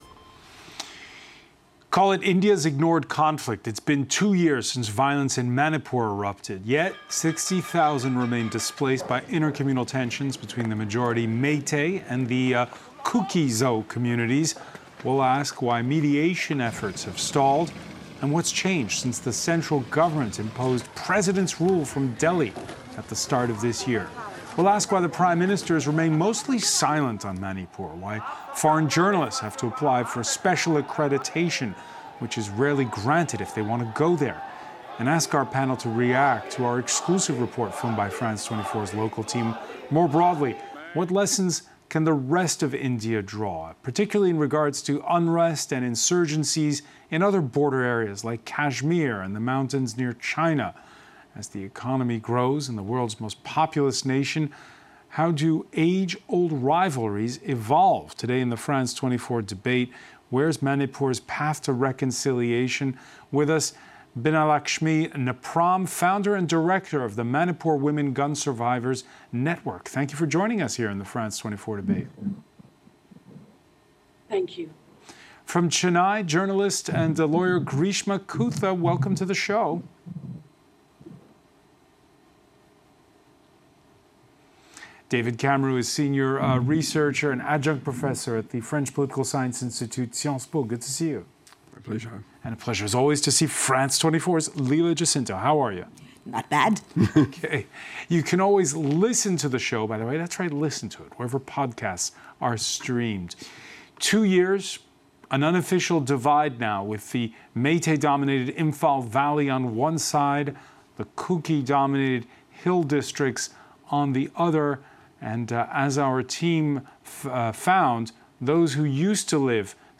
A live debate on the topic of the day, with four guests.